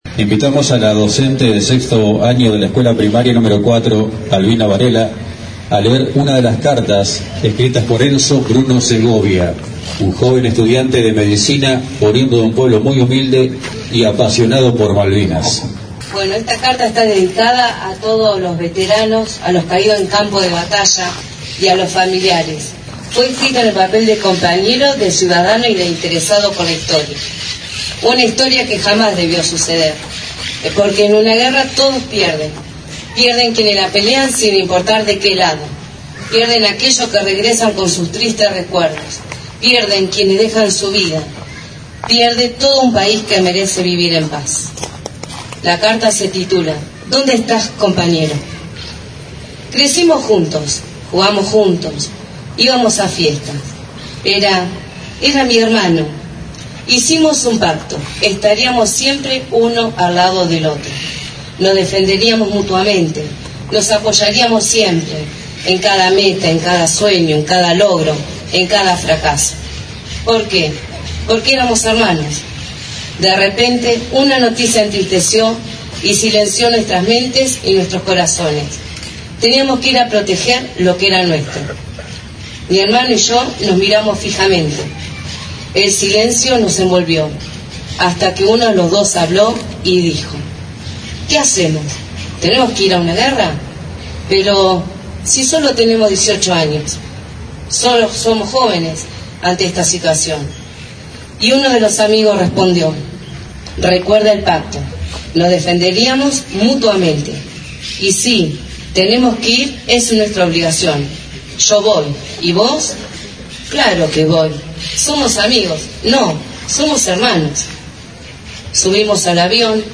AUDIO DEL ACTO
Luego llegó la desconcentración del acto con los canticos de la Marcha de Las Malvinas.